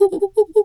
monkey_2_chatter_04.wav